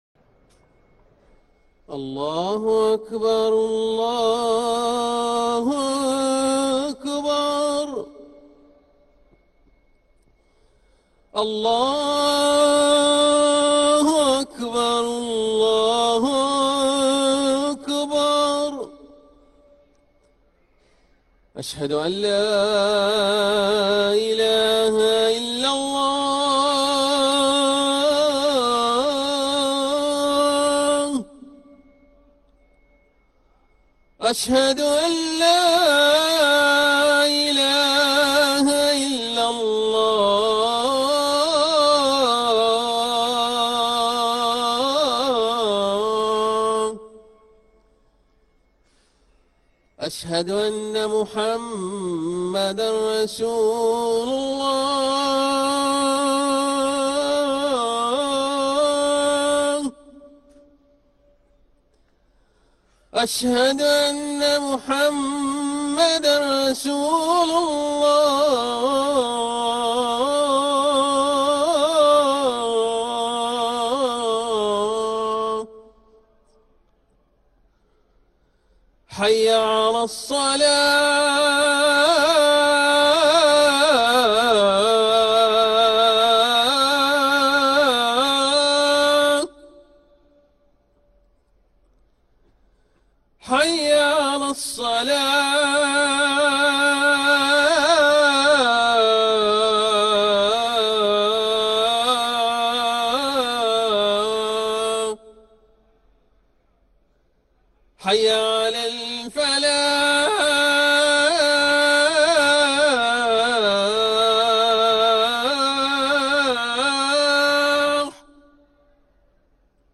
أذان العصر للمؤذن